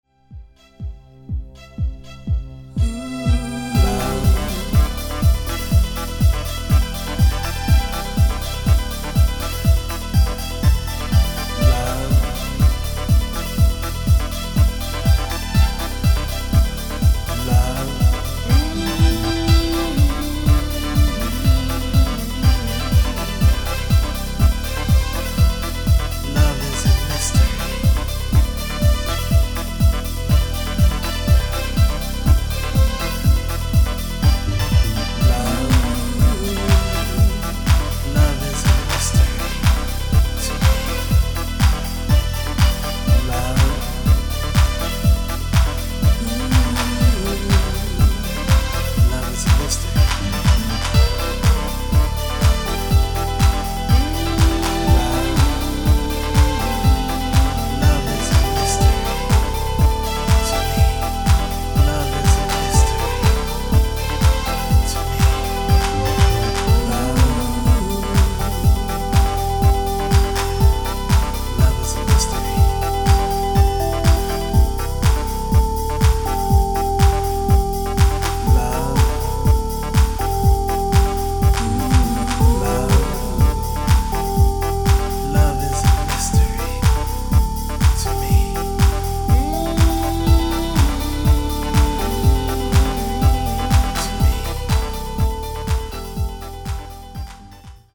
ムーディーでアンダーグランドな傑作ディープ・ハウスを計4トラック収録！
[90SHOUSE]